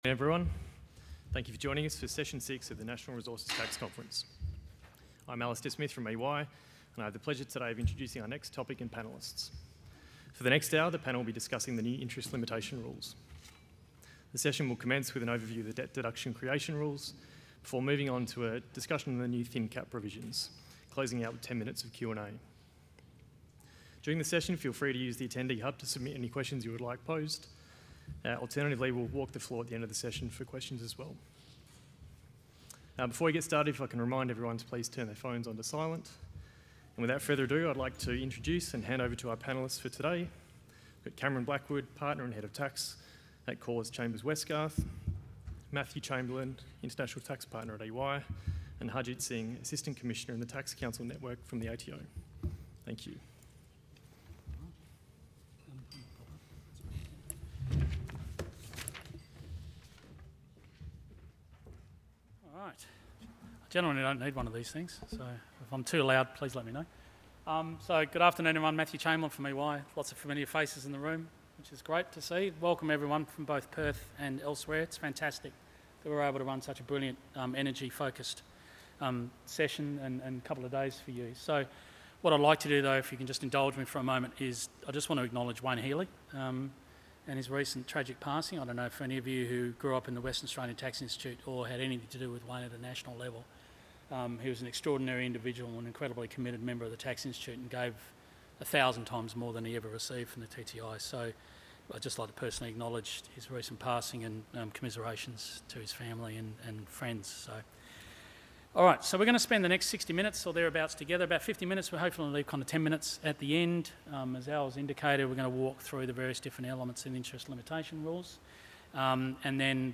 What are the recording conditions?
Event Name: National Resources Tax Conference Took place at: The Westin Perth